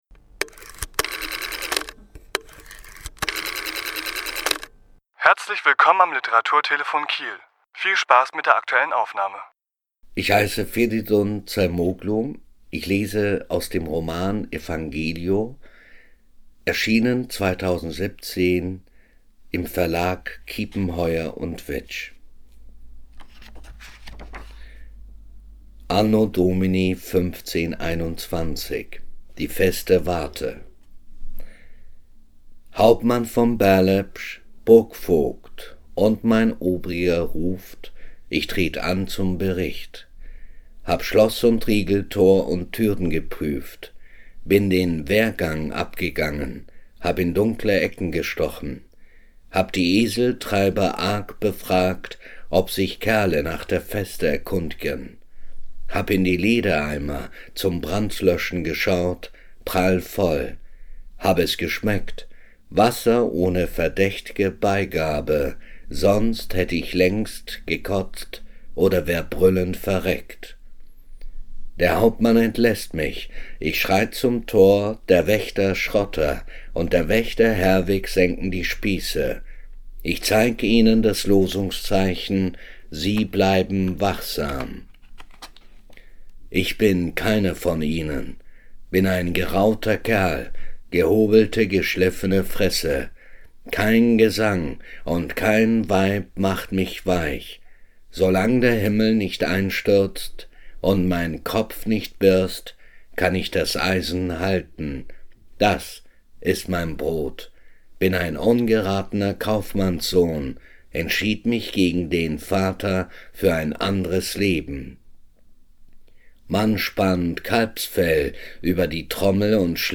Autor*innen lesen aus ihren Werken
Die Aufnahme entstand am 26.7.2017 bei einem Besuch in seiner Kieler Schreibstube.